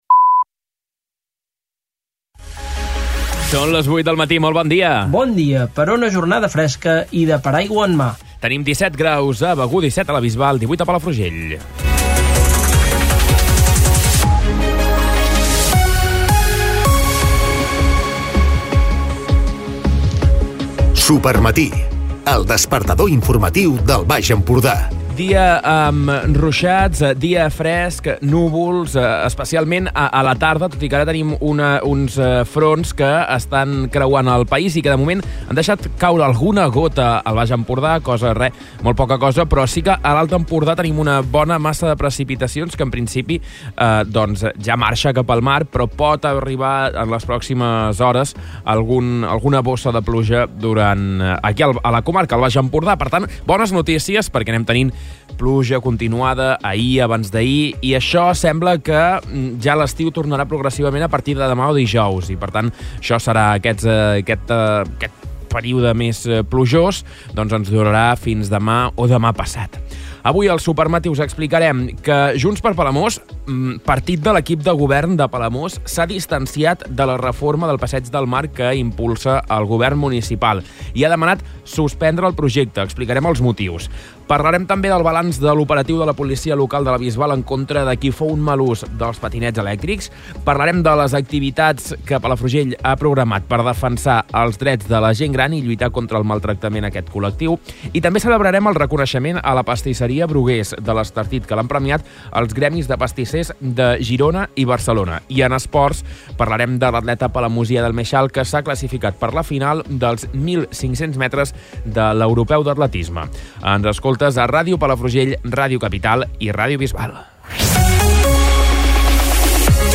Escolta l'informatiu d'aquest dimarts